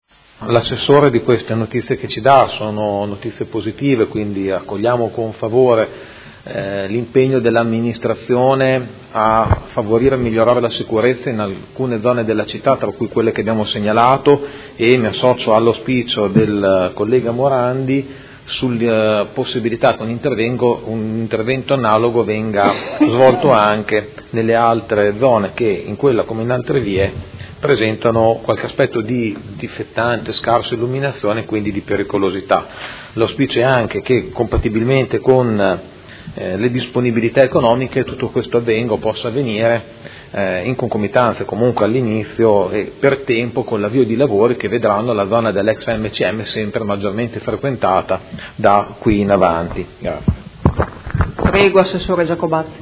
Seduta del 23/03/2017 Replica a risposta Assessore. Interrogazione del Consigliere Pellacani (FI) avente per oggetto: Attraversamento pedonale pericoloso Via C. Sigonio, altezza cinema estivo/parcheggio ex AMCM.